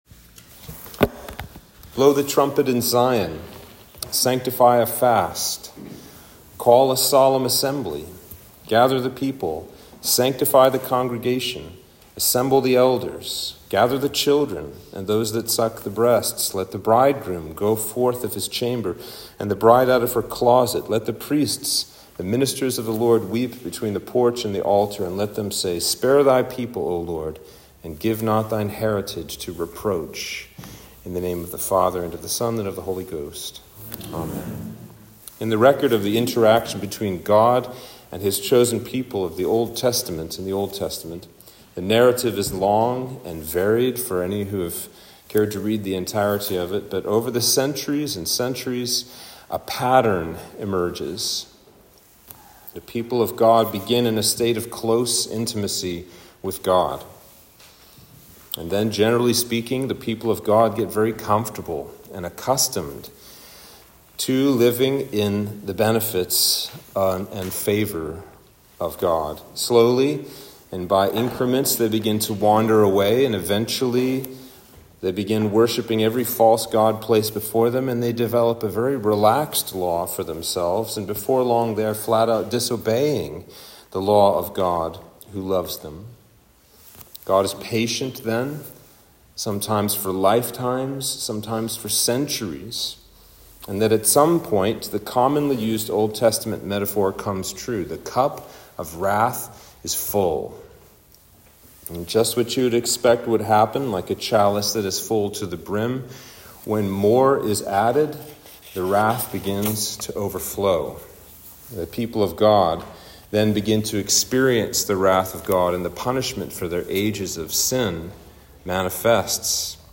Sermon for Ash Wednesday